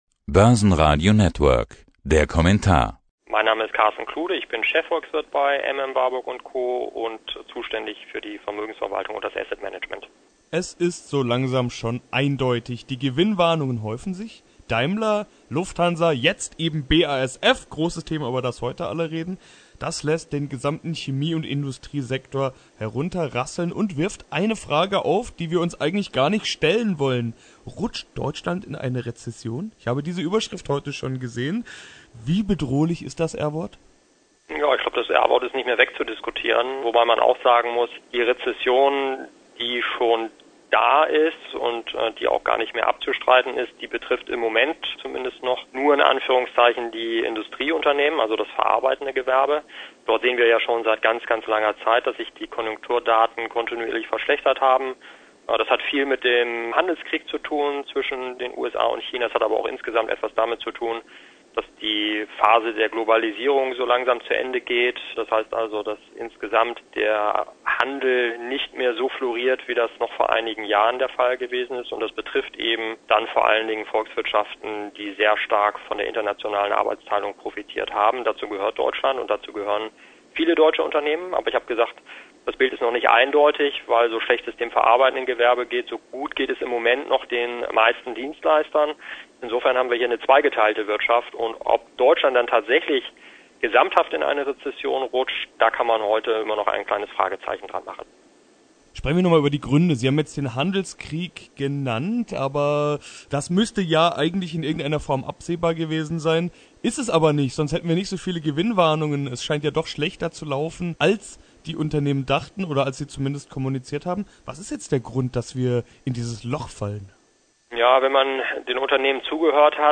Weitere Informationen Zum Interview